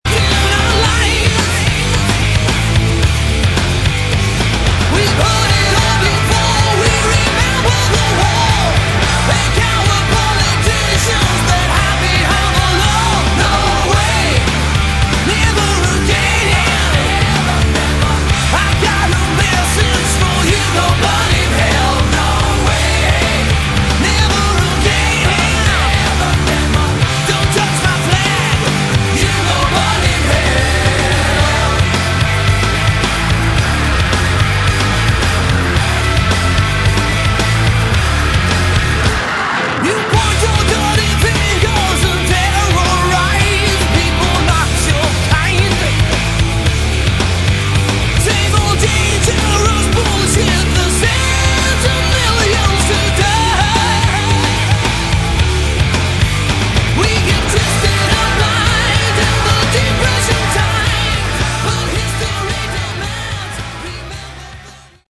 Category: Hard Rock
lead vocals
keyboards
bass
lead guitar
drums